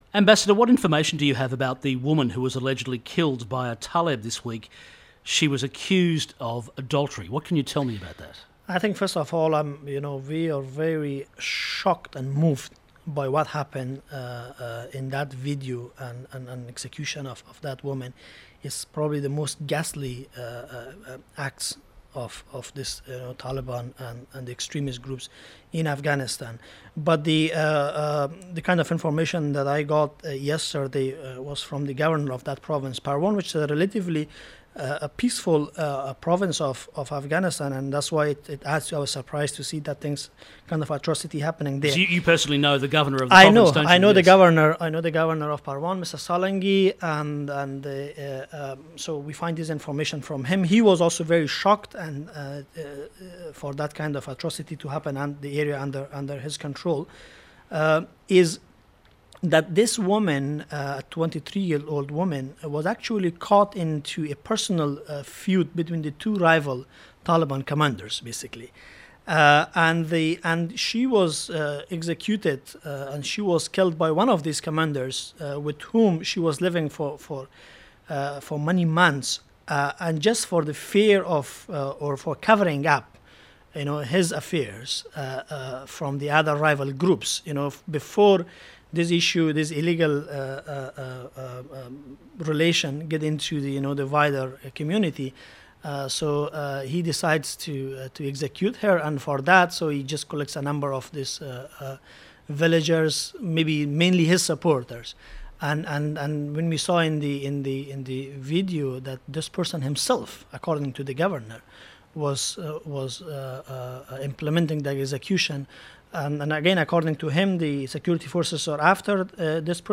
Ambassador Anhisha's response to the public execution in Afghanistan of a 23 year-old woman captured in a video can be heard in this ABC interview that was conducted soon after the meeting.